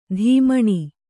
♪ dhīmaṇi